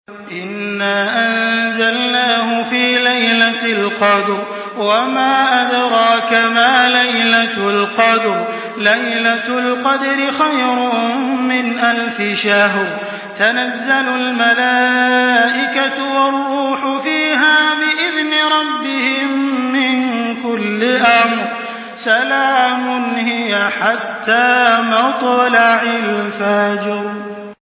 Surah আল-ক্বাদর MP3 in the Voice of Makkah Taraweeh 1424 in Hafs Narration
Surah আল-ক্বাদর MP3 by Makkah Taraweeh 1424 in Hafs An Asim narration.
Murattal